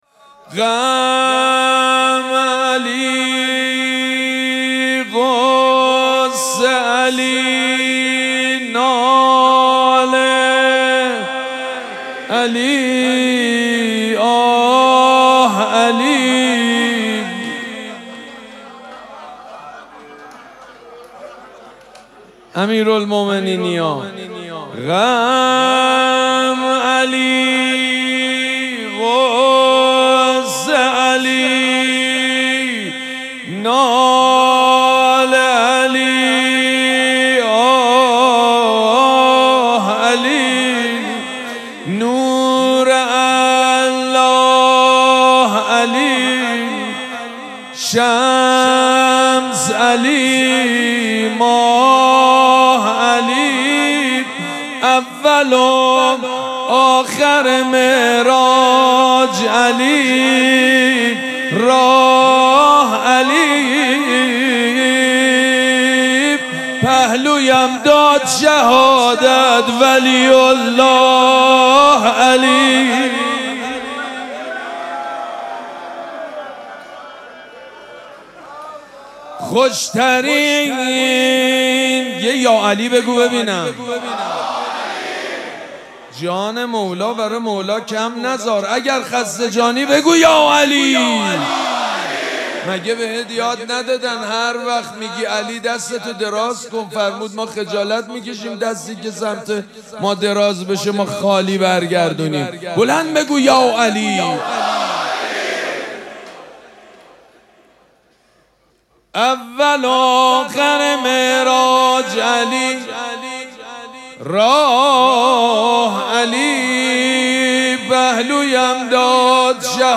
شب سوم مراسم عزاداری دهه دوم فاطمیه ۱۴۴۶
حسینیه ریحانه الحسین سلام الله علیها
شعر خوانی
مداح
حاج سید مجید بنی فاطمه